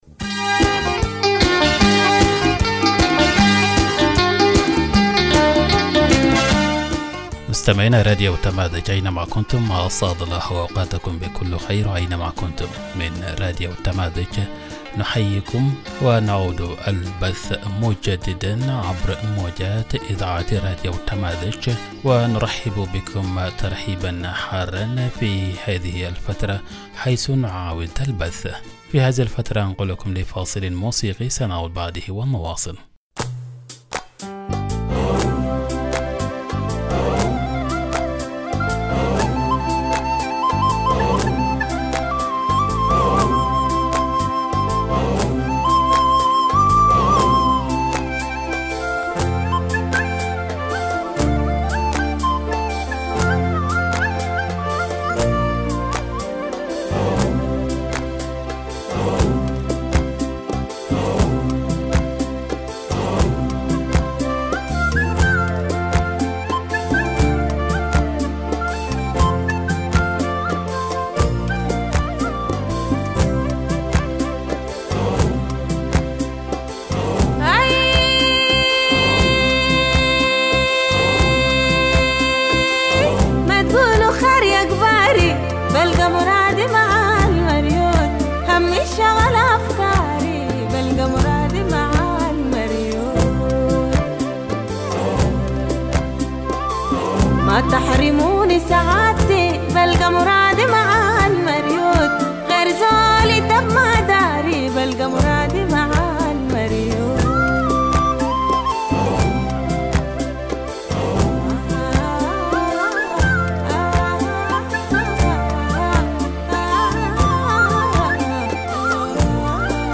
Morning Broadcast 03 April - Radio Tamazuj